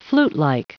Prononciation du mot flutelike en anglais (fichier audio)
Prononciation du mot : flutelike